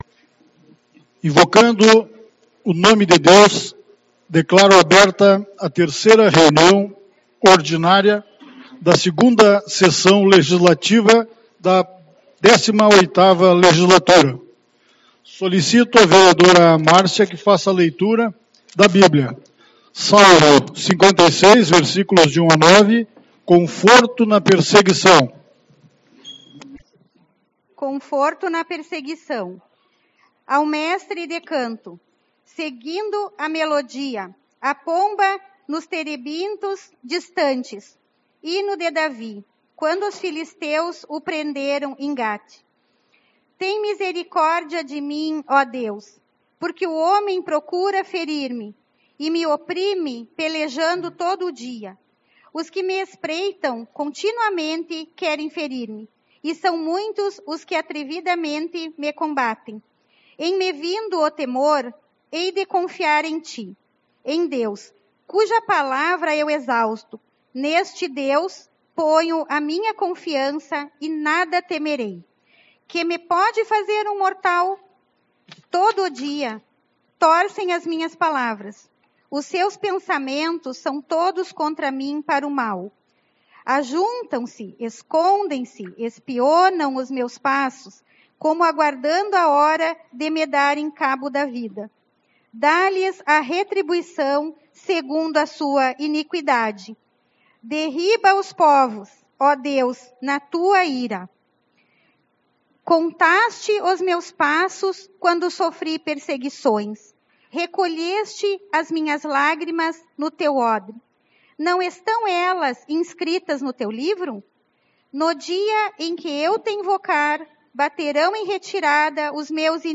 10/02 - Reunião Ordinária